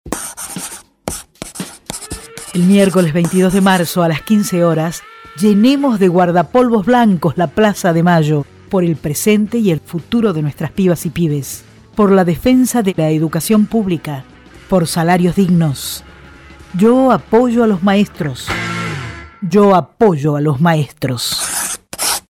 MARCHA FEDERAL EDUCATIVA - spot radial 7